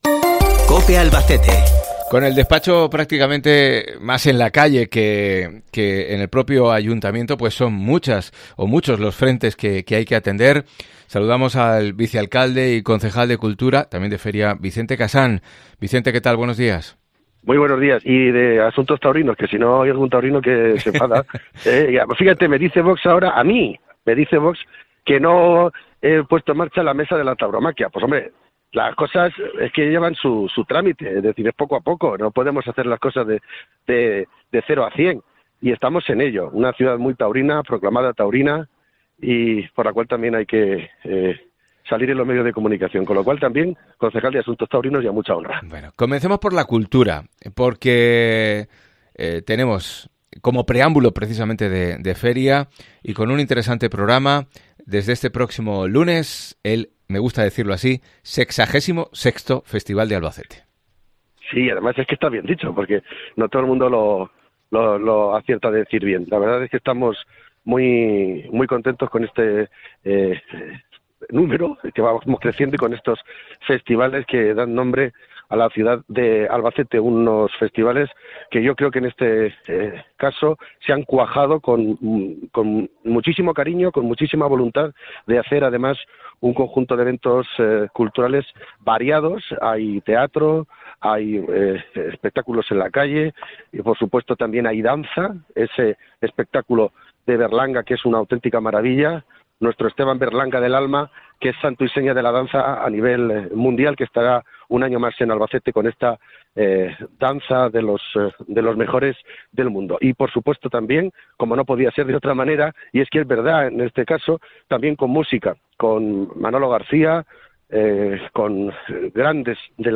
Vicente Casañ, concejal de Feria del Ayuntamiento de Albacete